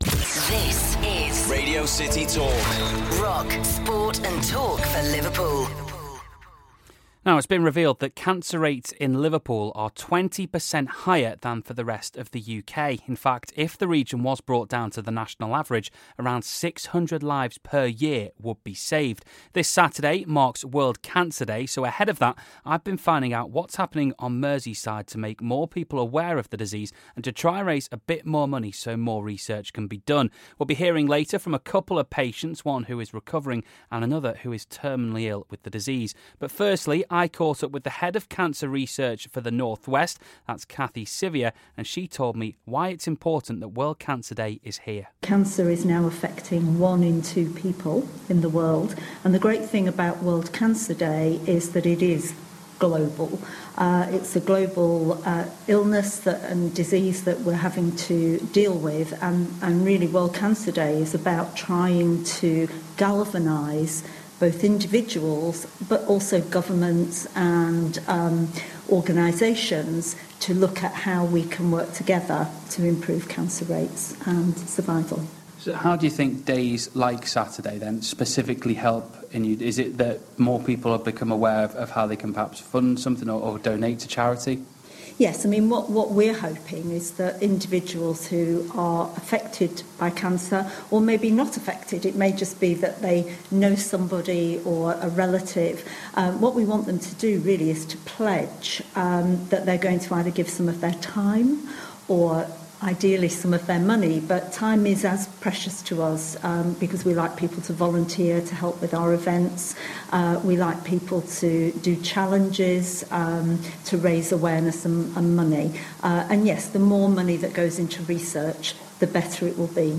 We found out why World Can Day is important by speaking to North West Cancer Research, The Clatterbridge Cancer Centre and two people who've been hit by the disease